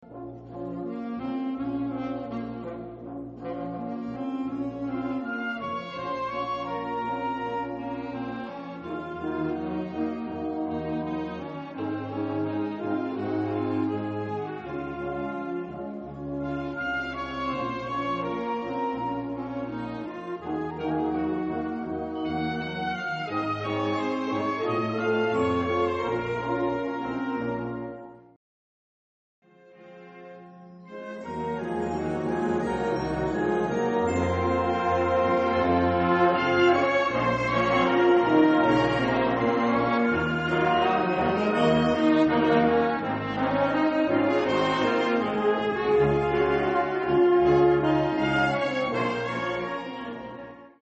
Damit die Flöten nicht gestört werden, nimmt das Altsaxophon die Lautstärke zurück, sobald sie einsetzen.
Später hat das Altsaxophon eine Verzierung zu spielen: Ein Es-Alt gegen 5 Trompeten und sonstiges Blech. Obwohl ich hier entgegen der Lautstärkeanweisung fortissimo blies, geht der Einwurf des Saxophons bis auf seine obersten Töne unter.
Pacis Valley: Altsaxophon hörbar?